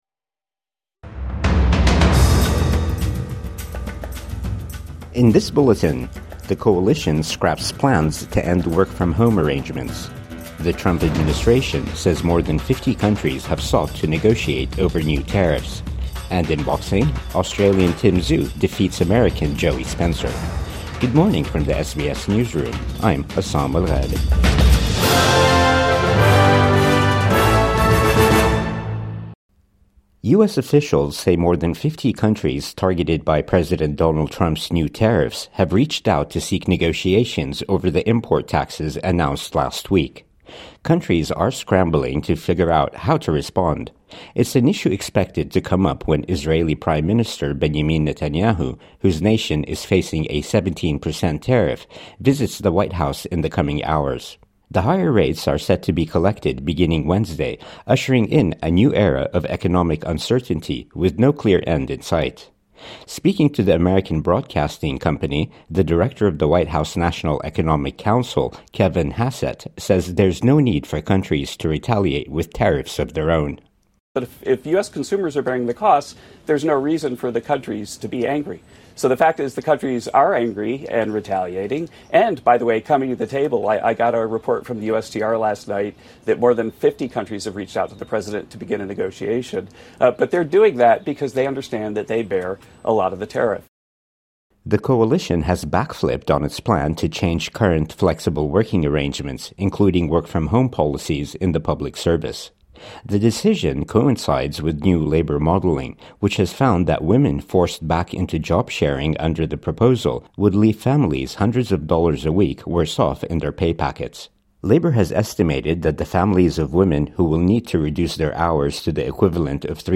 Countries scramble to negotiate over US tariffs |Morning News Bulletin 7 April 2025